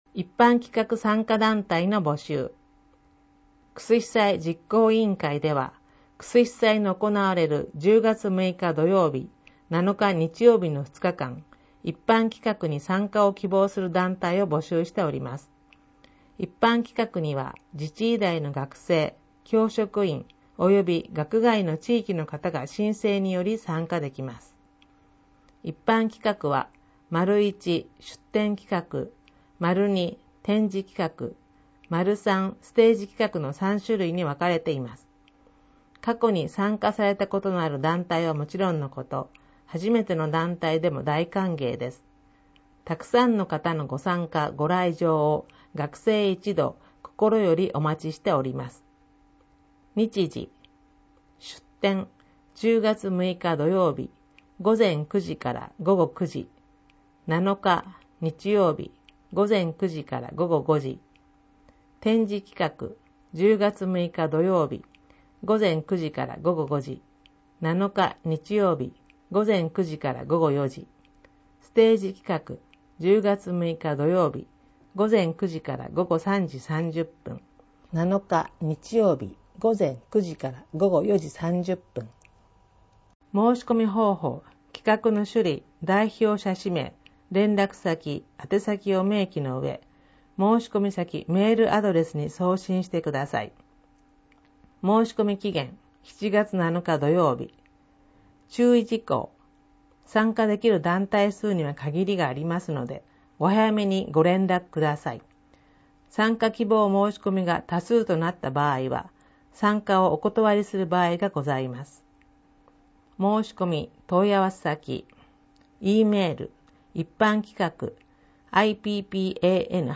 音声ファイルで読み上げられるページ番号はデイジー版（←無償配布を行っています。くわしくはこちらをクリックしてください。）用となっております。